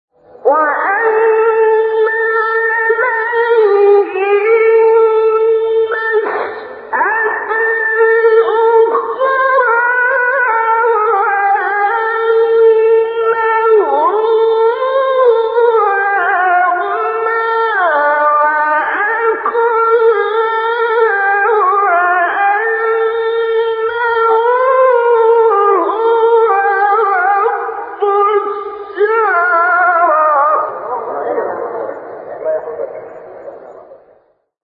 آیه 47-49 سوره نجم استاد شعیشع | نغمات قرآن | دانلود تلاوت قرآن